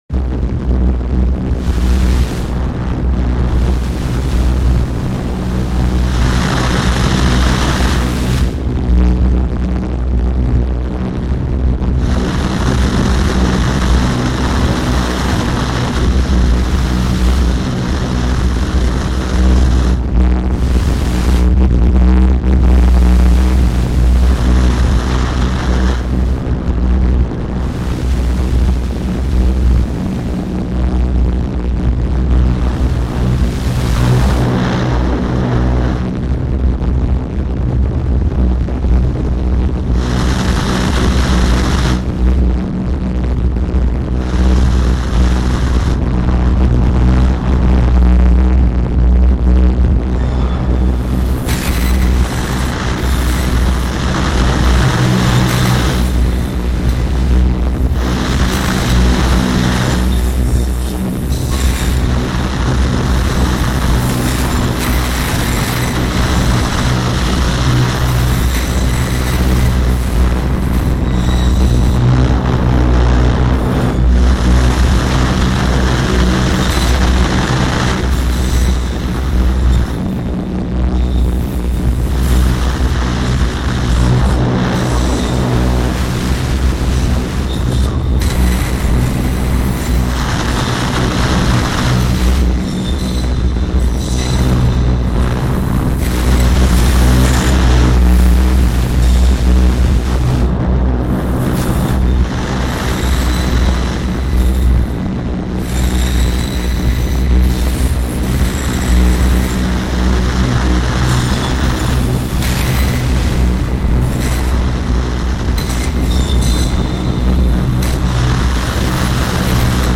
Meat preparation in Whitechapel reimagined